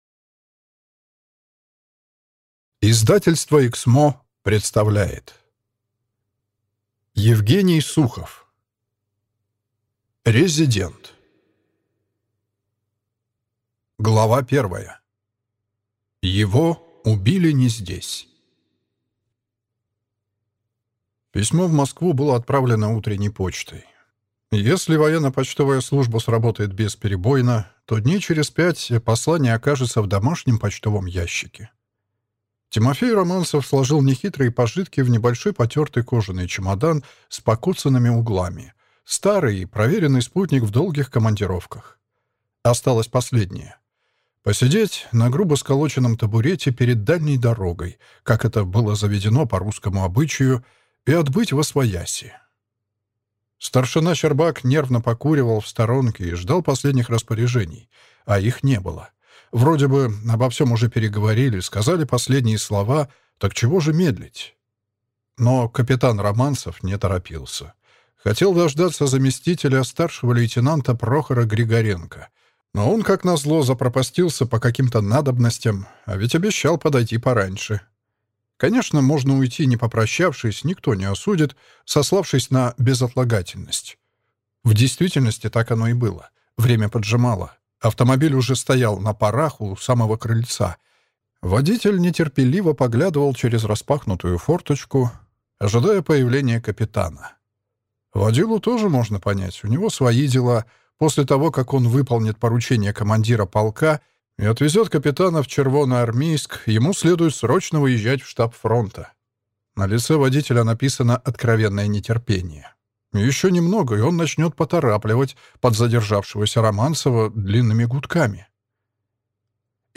Аудиокнига Резидент | Библиотека аудиокниг